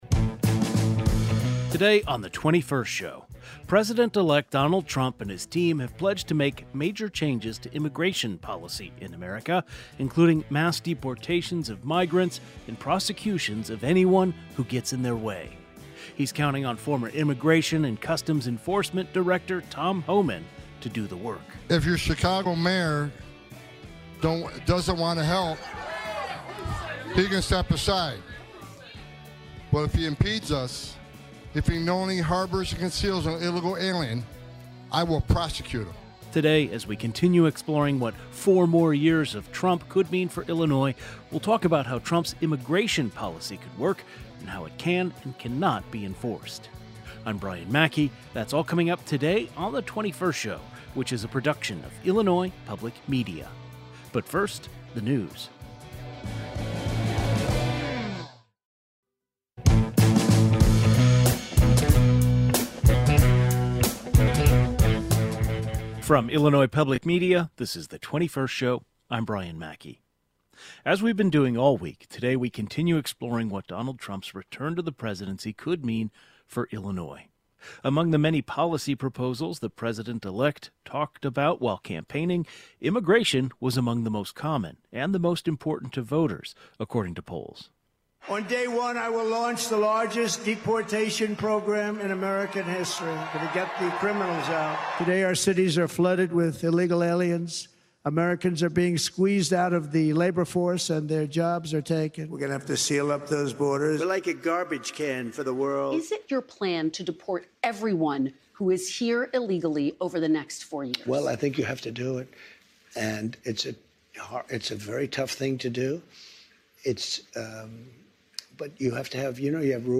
President-Elect Donald Trump has pledged to make massive changes to immigration policy in America. A panel of immigration experts who work with people who may be affected by the proposed changes discuss ICE, deporations, H1-B visas, polIcies surrounding migrants and refugees, and how advocates are preparing for the next four years.